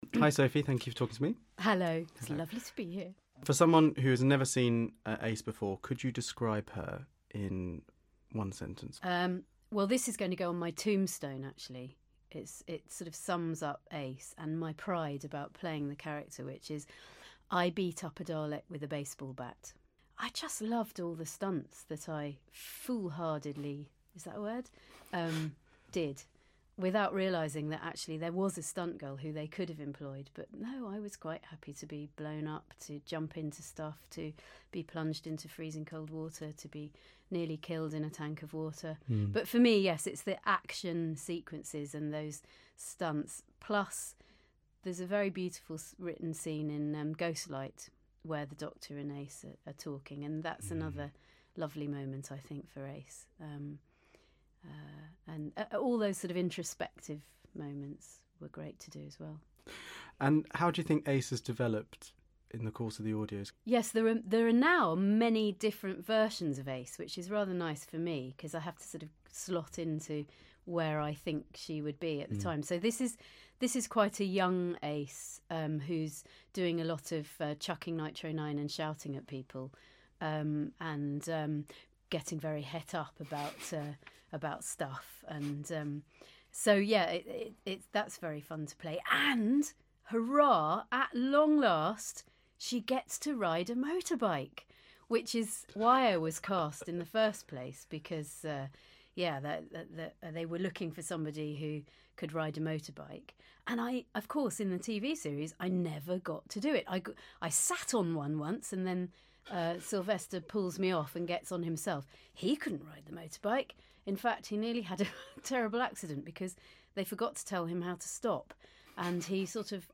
Seventh Doctor companion Ace, better known as actor Sophie Aldred, spoke to us at a recent Big Finish recording about playing her character.